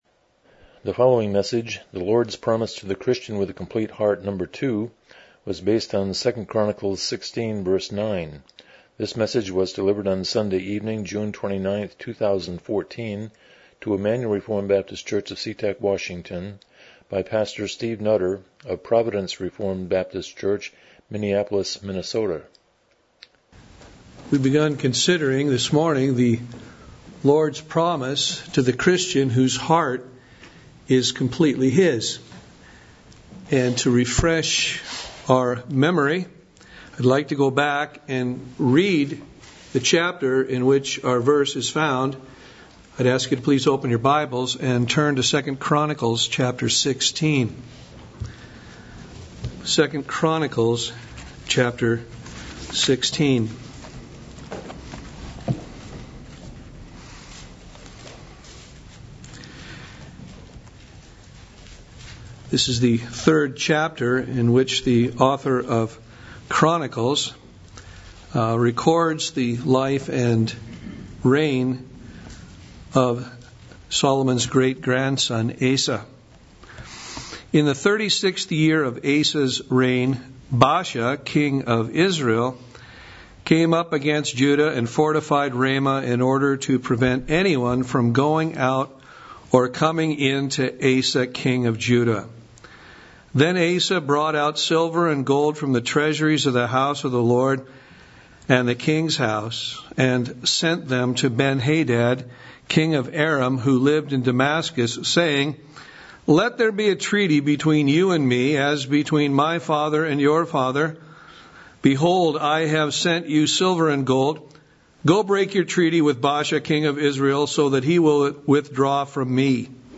Passage: 2 Chronicles 16:9 Service Type: Evening Worship